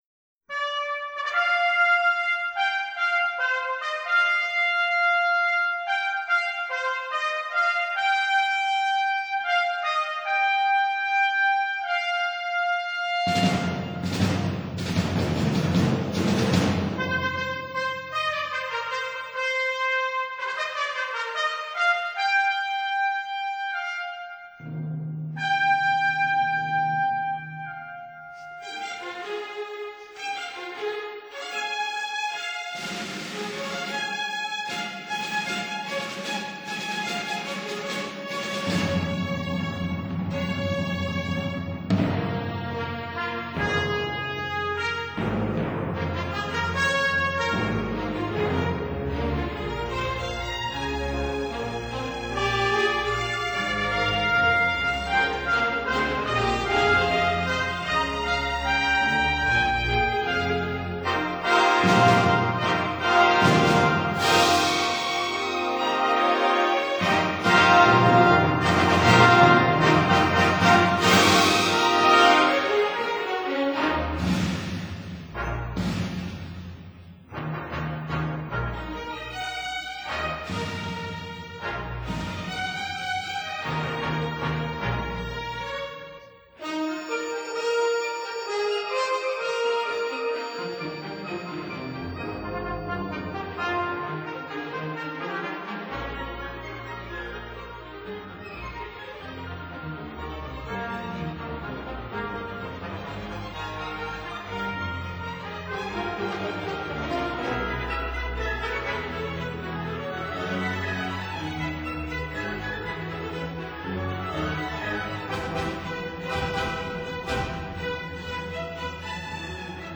conductor